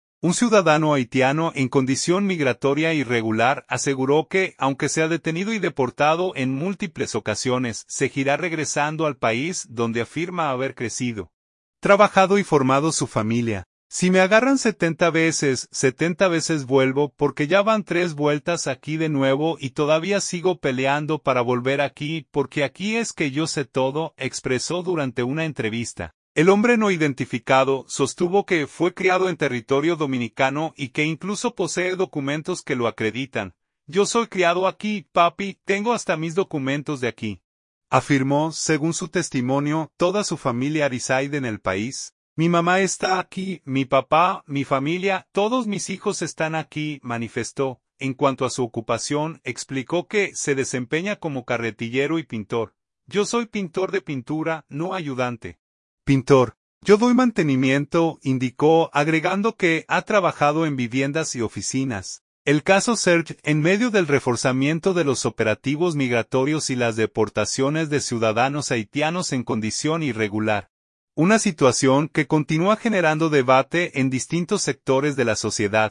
“Si me agarran 70 veces, 70 veces vuelvo, porque ya van tres vueltas aquí de nuevo y todavía sigo peleando para volver aquí, porque aquí es que yo sé todo”, expresó durante una entrevista.